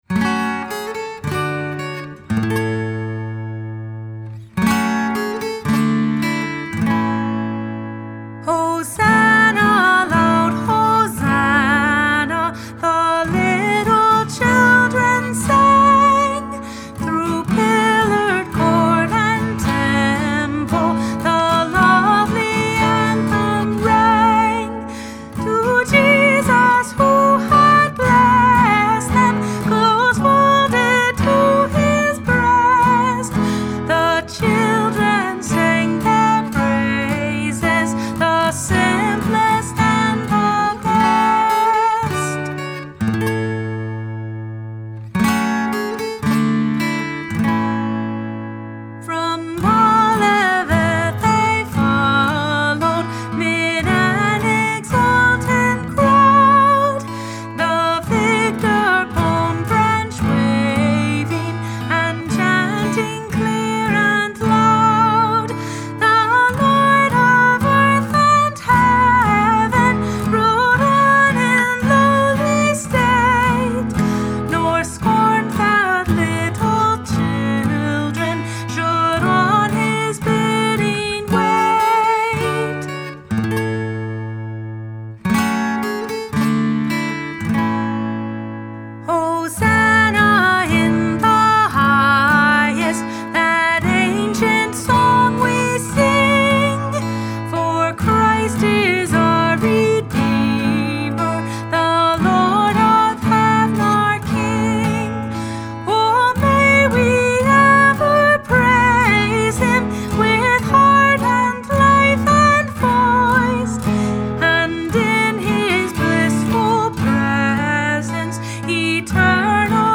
Hymn
The Hymnal Project